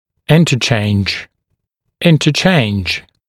[‘ɪntəʧeɪnʤ] гл. [ˌɪntə’ʧeɪnʤ][‘интэчейндж] гл. [ˌинтэ’чейндж]взаимный обмен, обмениваться